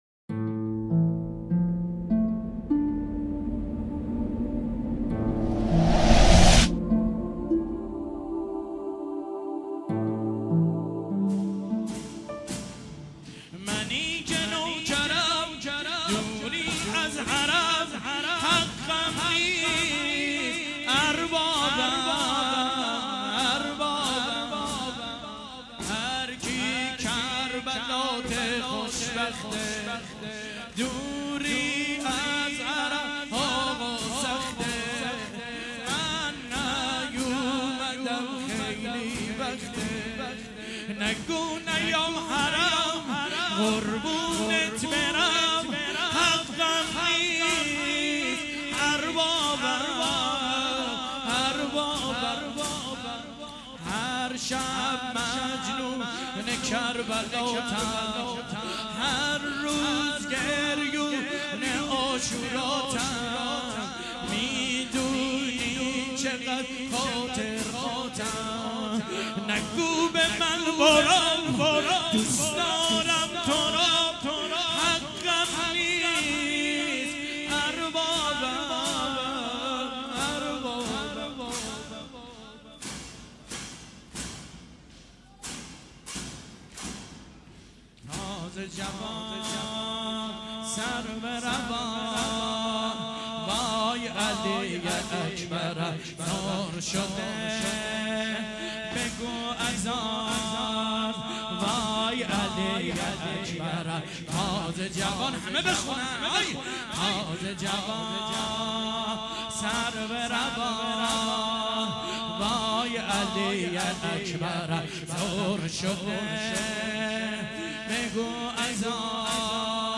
زنجیـر زنـی | شب هشتم محرم 1397 | هیأت غریب مدینه